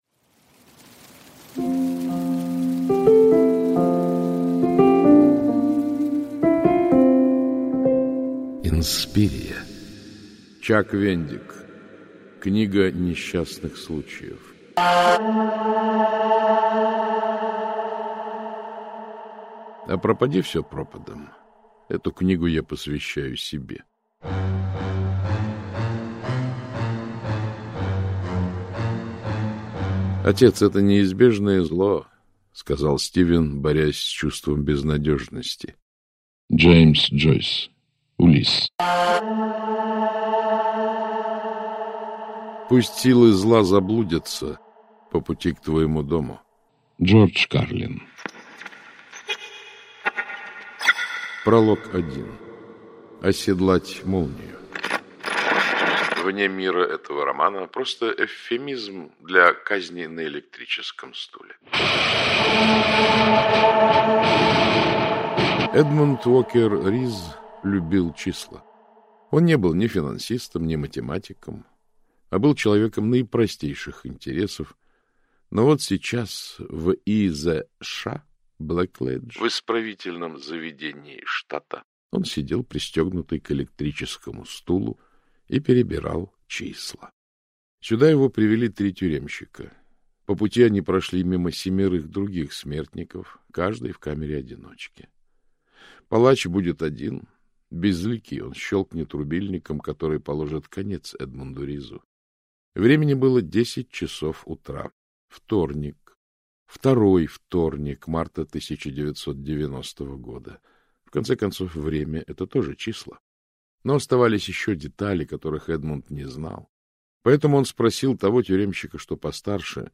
Аудиокнига Книга несчастных случаев | Библиотека аудиокниг